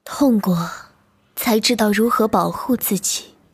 girl_05.mp3